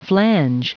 Prononciation du mot flange en anglais (fichier audio)
Prononciation du mot : flange